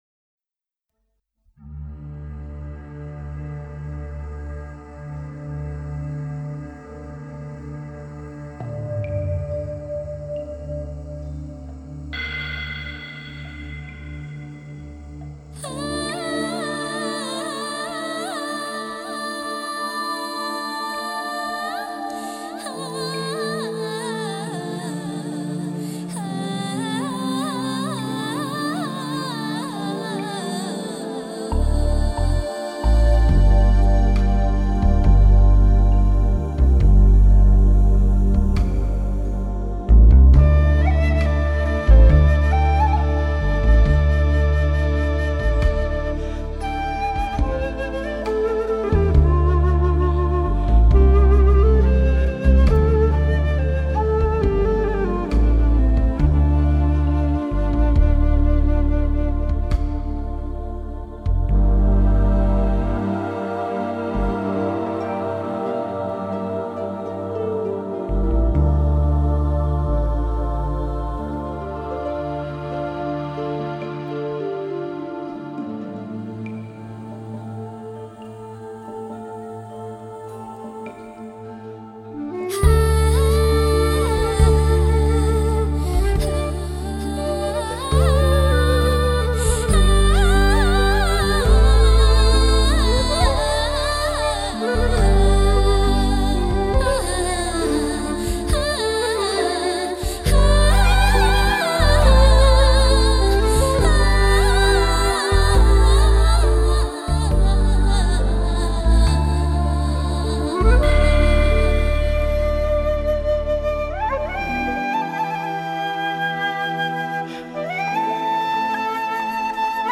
0014-蓝花花－箫.mp3